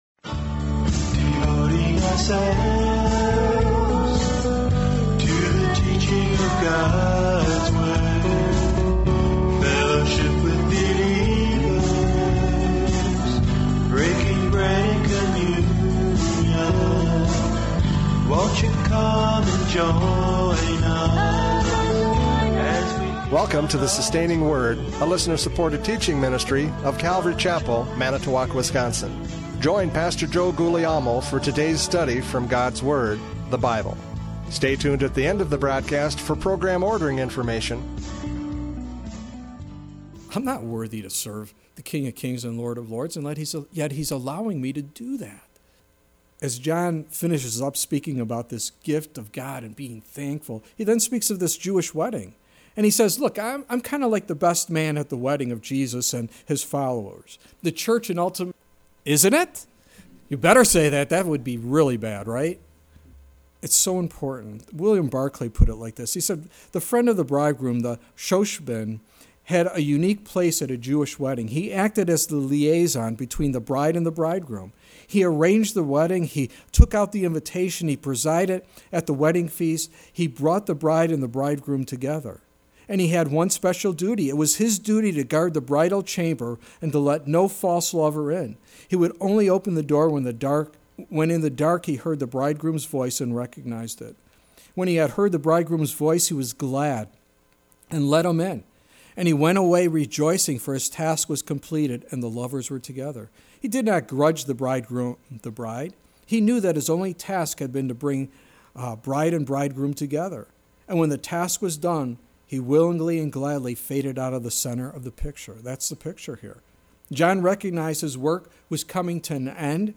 John 3:22-36 Service Type: Radio Programs « John 3:22-36 Absolute Surrender!